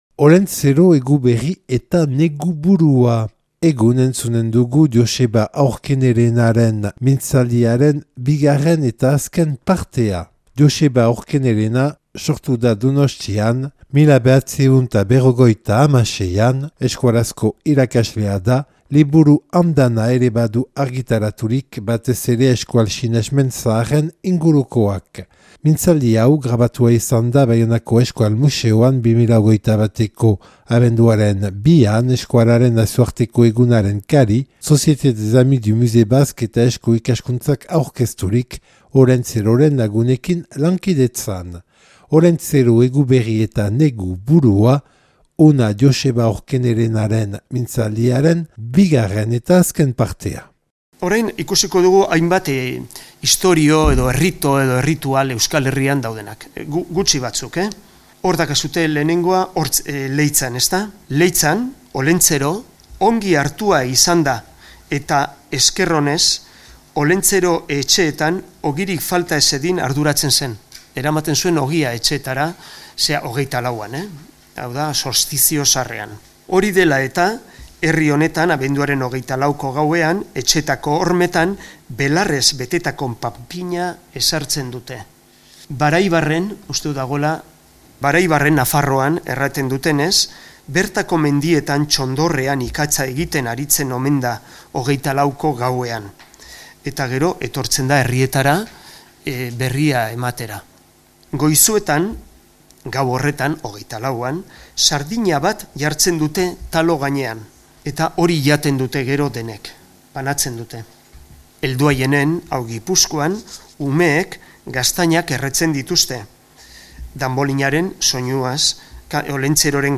(Baionako Euskal Museoan grabatua 2021. Abenduaren 2an)